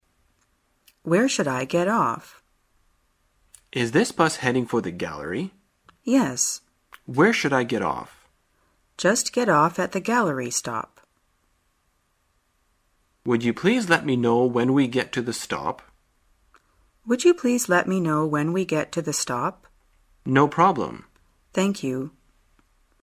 在线英语听力室生活口语天天说 第81期:怎样询问下车站点的听力文件下载,《生活口语天天说》栏目将日常生活中最常用到的口语句型进行收集和重点讲解。真人发音配字幕帮助英语爱好者们练习听力并进行口语跟读。